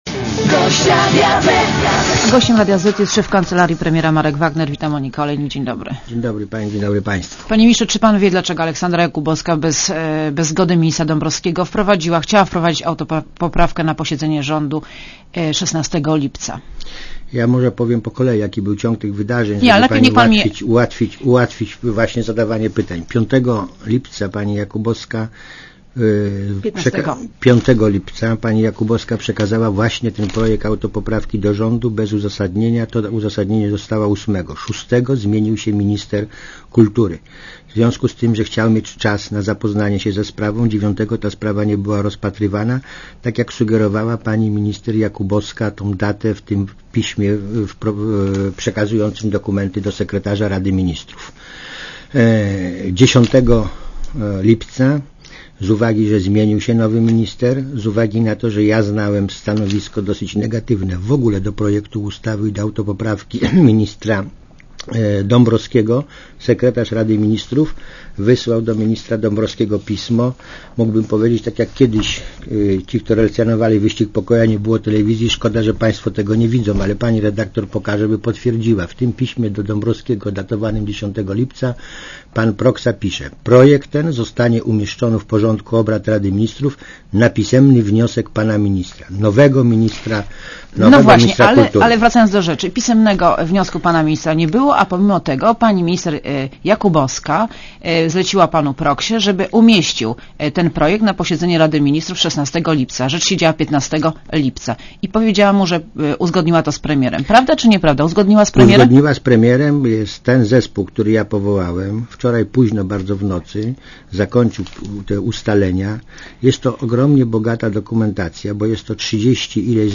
: Gościem Radia Zet jest szef Kancelarii Premiera Marek Wagner.
Posłuchaj wywiadu : Gościem Radia Zet jest szef Kancelarii Premiera Marek Wagner .